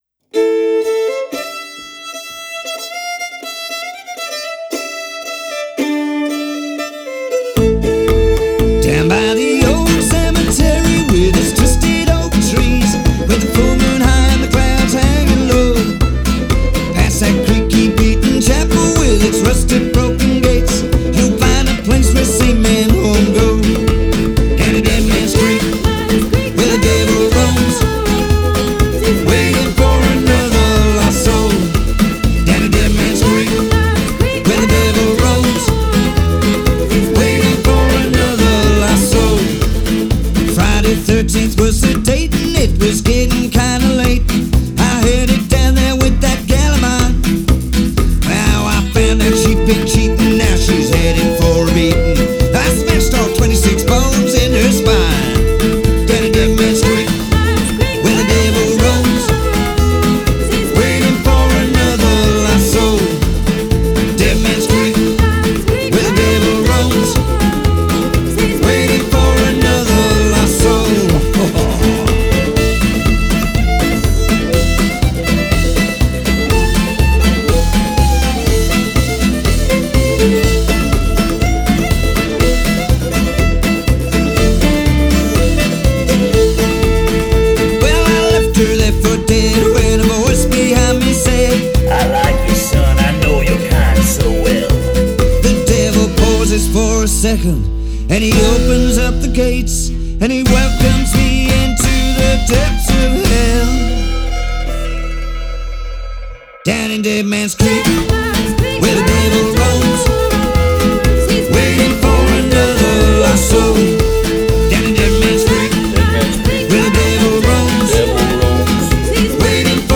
English-speaking Country and Folk music